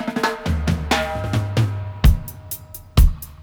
121-FILL-FX.wav